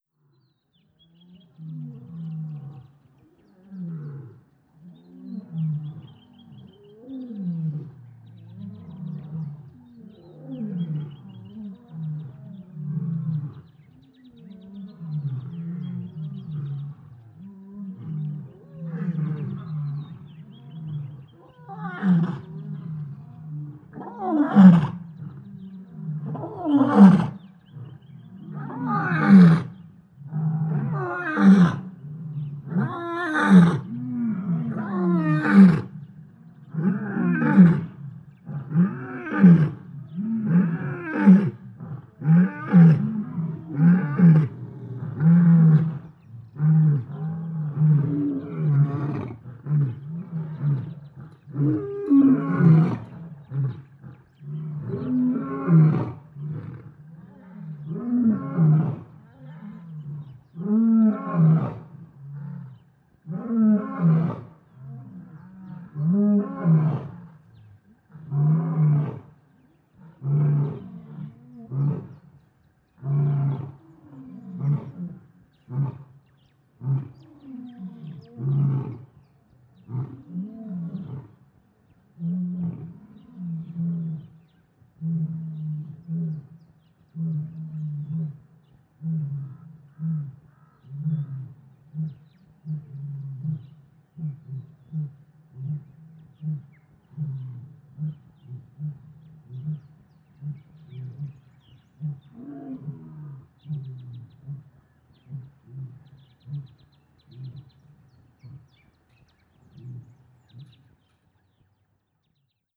• lions in savannah.wav
lions_in_savannah_mYy.wav